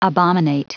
Prononciation du mot abominate en anglais (fichier audio)
Prononciation du mot : abominate